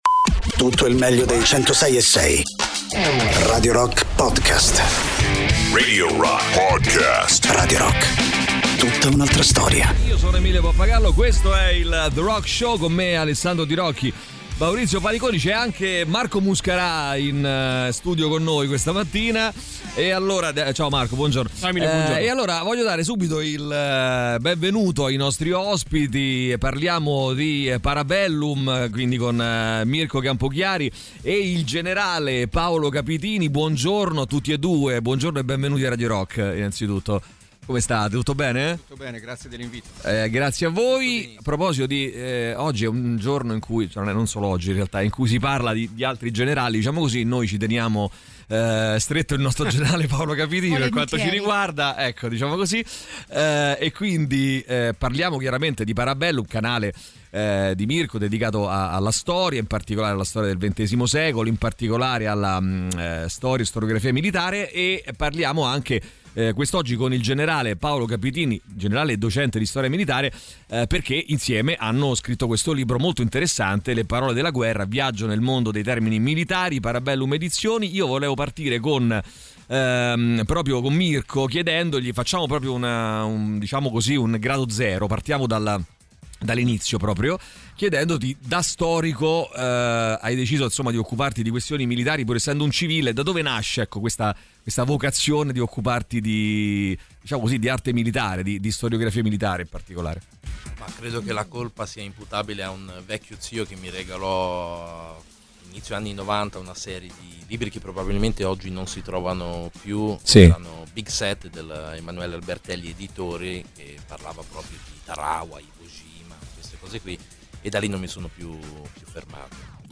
Interviste
ospiti in studio